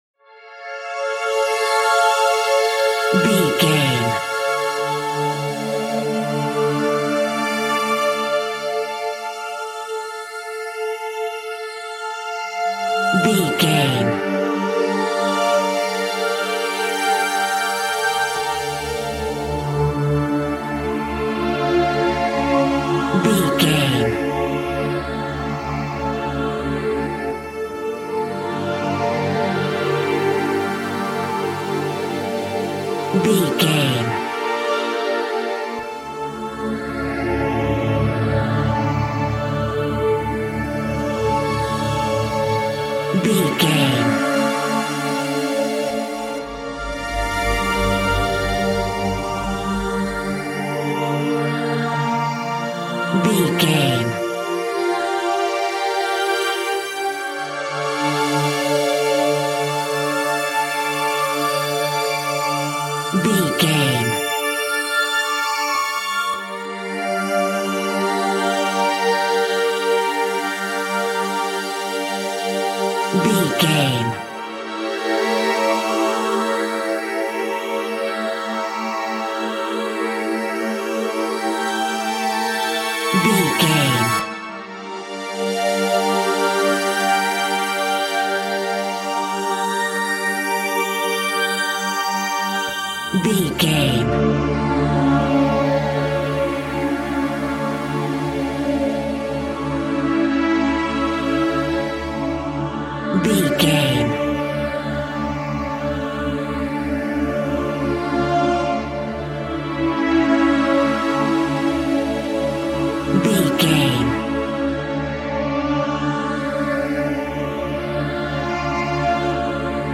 Atmosphere Haunting In Space.
Aeolian/Minor
E♭
ominous
haunting
eerie
synthesizer
Horror Ambience
dark ambience
Synth Pads
Synth Ambience